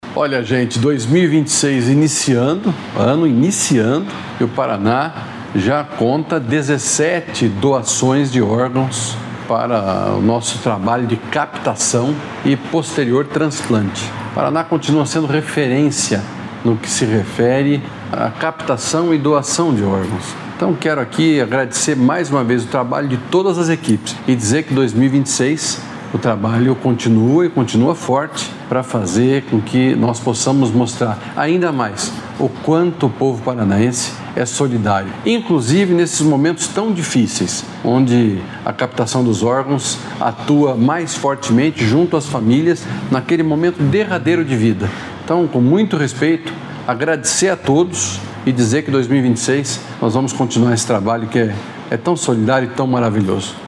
Sonora do secretário da Saúde, Beto Preto, sobre o número de doações em janeiro de 2026 | Governo do Estado do Paraná